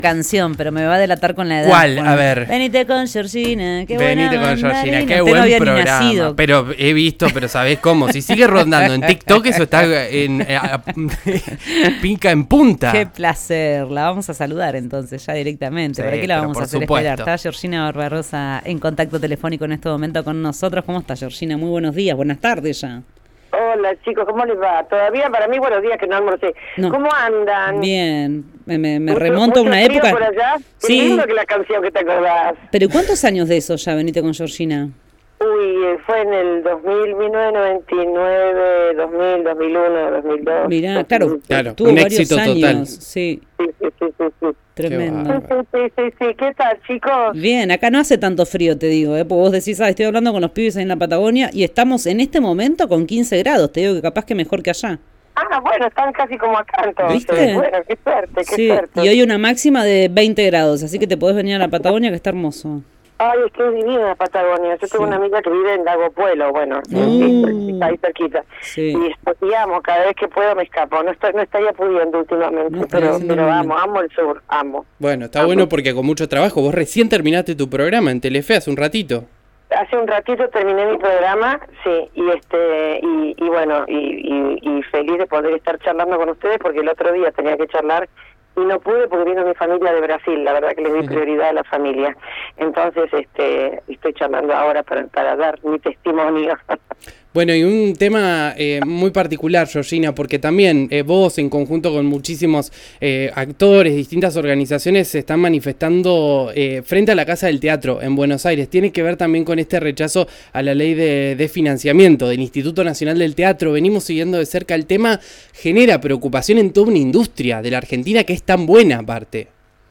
En una entrevista con Diario del Mediodía de Río Negro Radio, Barbarossa expresó su preocupación por los recortes al Instituto Nacional del Teatro (INT) y la situación crítica de la industria cultural en Argentina.